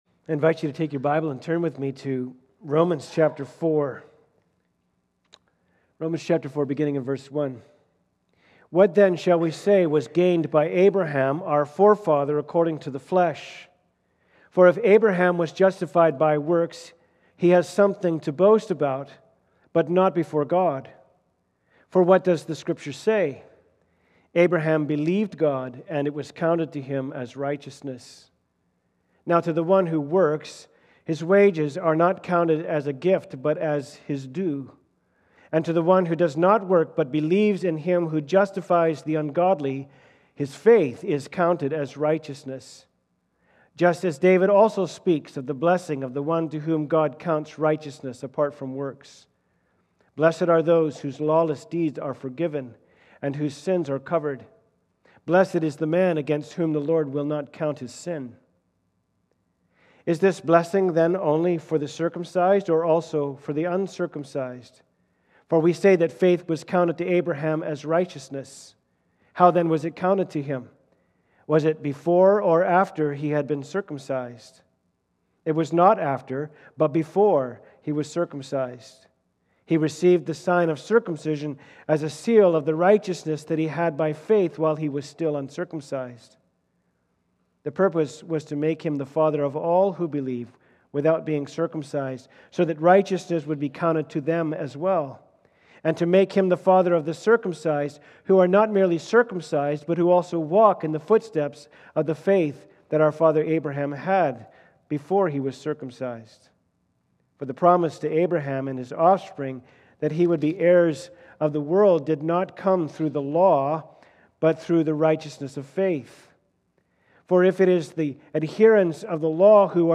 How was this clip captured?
Romans 4:1-25 Service Type: Sunday Service « “Thy Will Be Done”